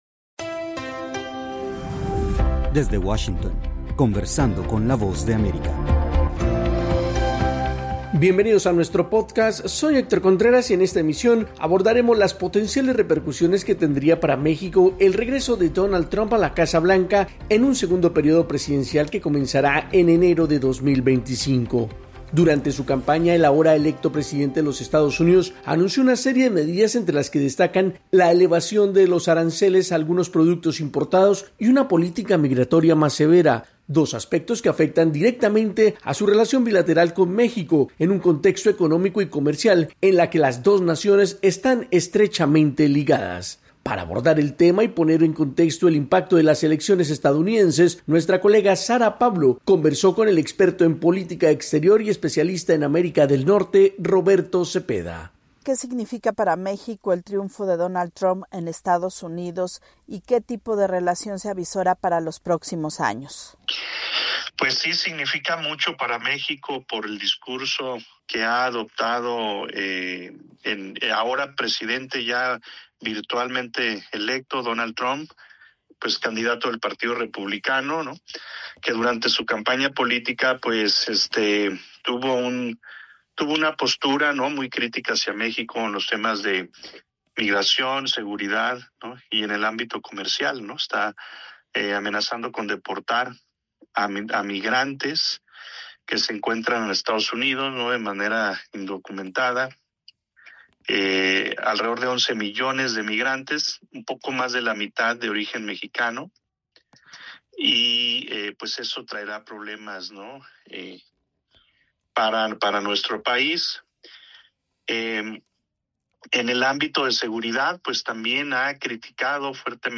La llegada de Donald Trump a la presidencia de EEUU pone en una situación complicada a México si se toman en cuenta las promesas de campaña del republicano. Para analizar la situación conversamos con el experto en política exterior y especialista en América del Norte